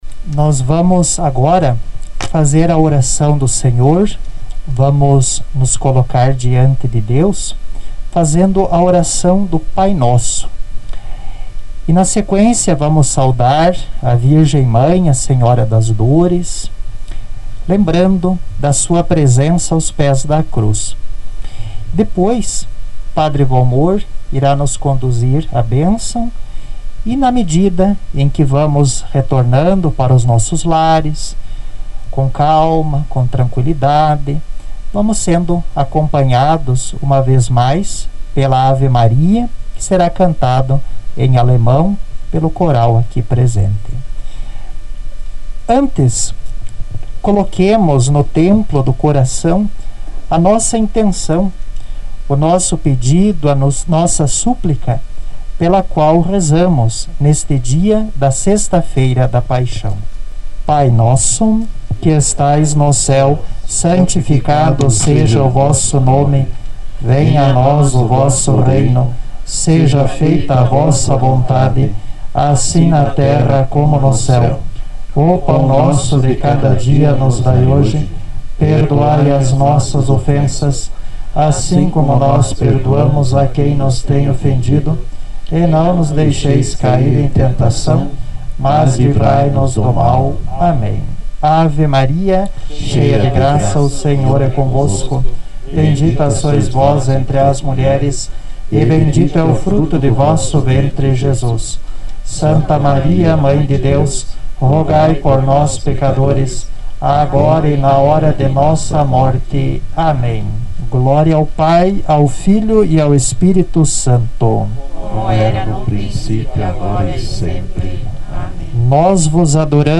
Todo o evento foi acompanhado pelo coral da Paróquia São Pedro e São Paulo que, ao final, cantou uma Ave Maria em Alemão.